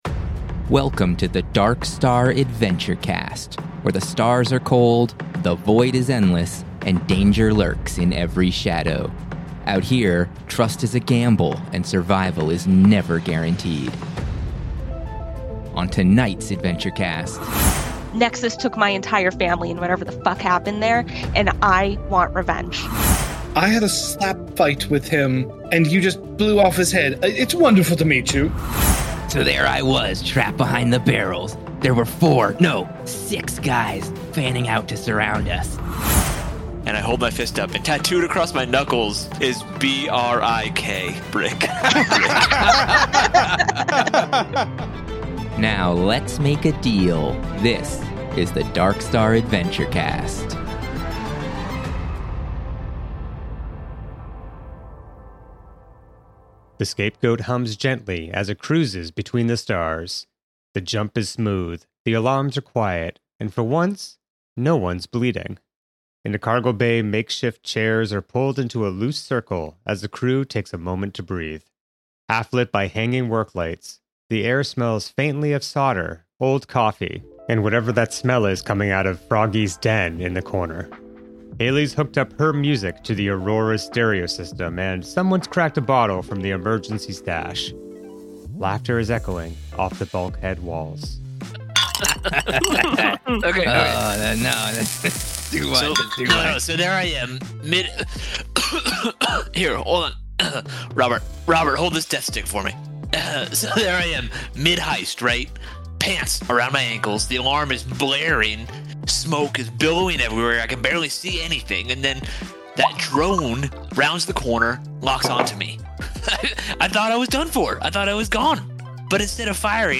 A sci-fi actual play podcast using the Stars Without Number TTRPG! Follow the intrepid crew of the Scapegoat as they navigate heists, gang wars, cosmic dangers, and political intrigue in the dangerous Veridian Expanse.